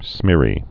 (smîrē)